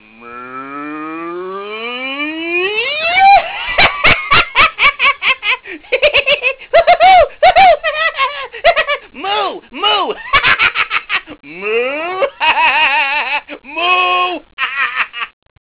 cow #2 (12k)
cow2.au